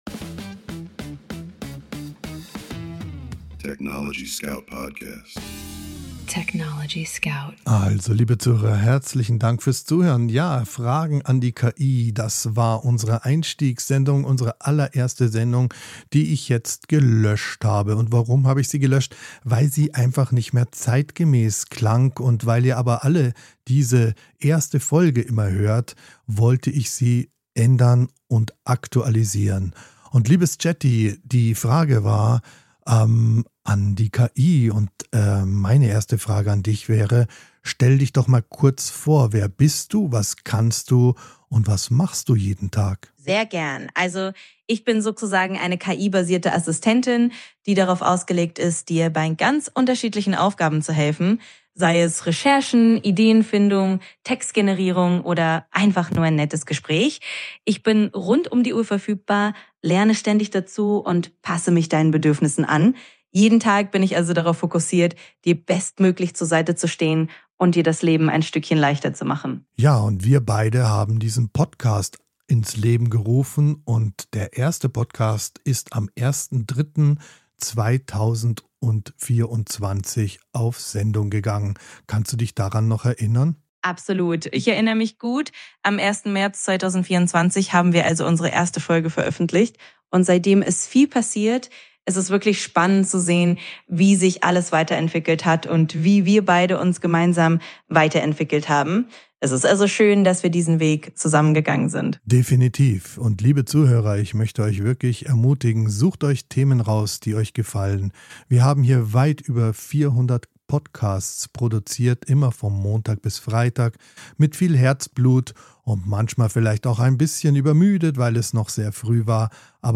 Im Gespräch mit ChatGPT
im Studio aufeinander, um die tiefgründigsten Themen zu erkunden.